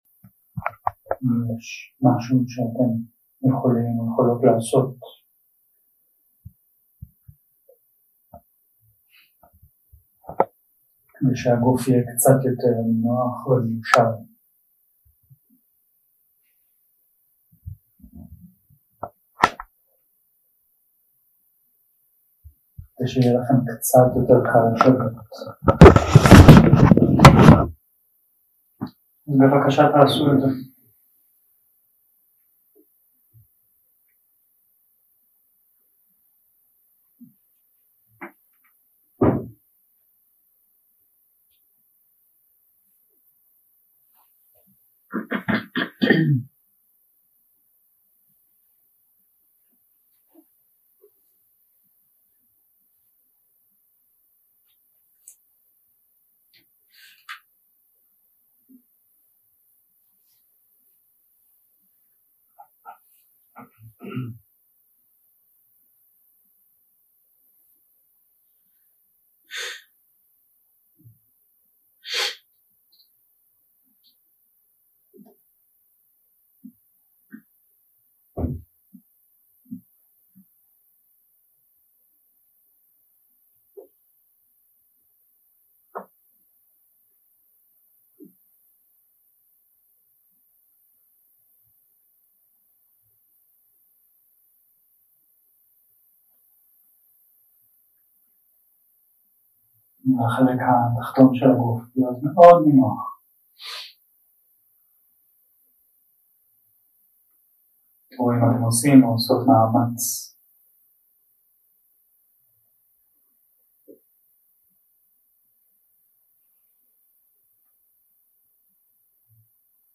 יום 1 – הקלטה 1 – ערב – מדיטציה מונחית – להיכנס לתרגול
Dharma type: Guided meditation שפת ההקלטה